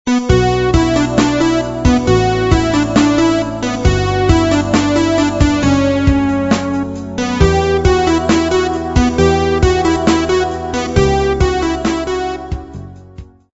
• Пример мелодии содержит искажения (писк).